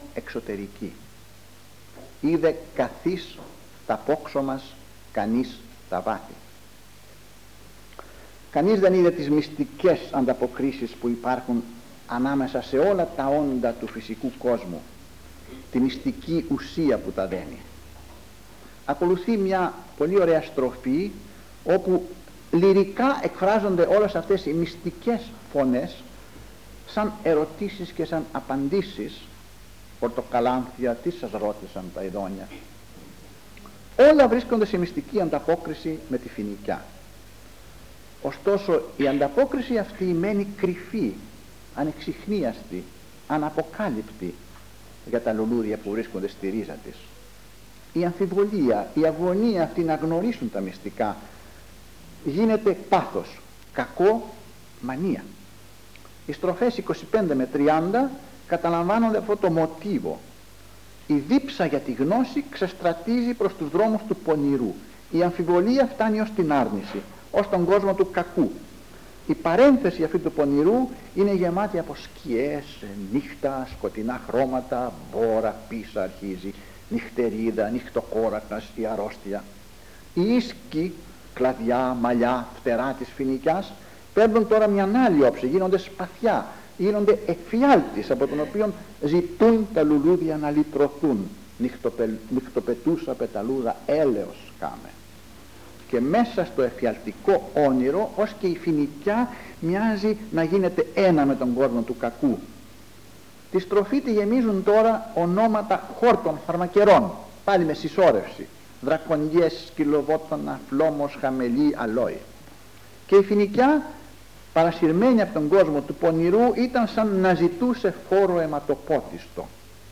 Τίτλος: Παρουσίαση και ανάγνωση της "Φοινικιάς" του Κωστή Παλαμά
Εξειδίκευση τύπου : Εκδήλωση
Εμφανίζεται στις Ομάδες Τεκμηρίων:Εκδηλώσεις λόγου